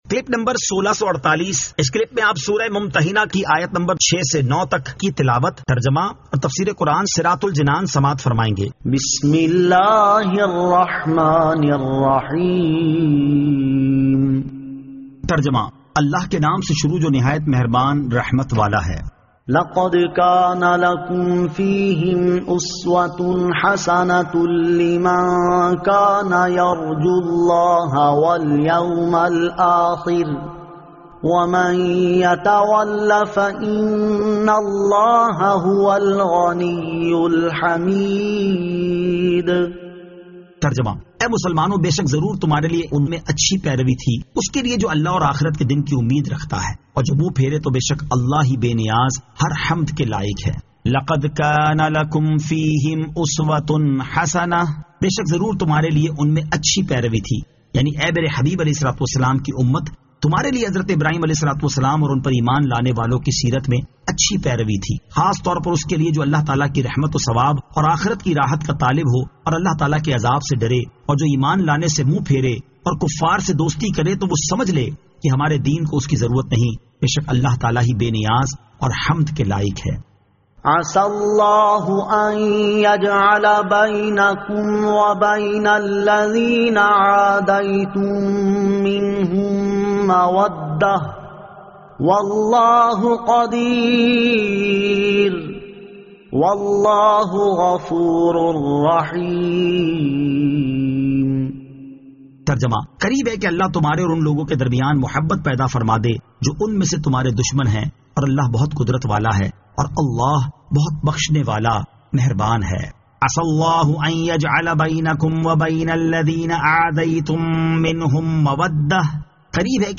Surah Al-Mumtahinan 06 To 09 Tilawat , Tarjama , Tafseer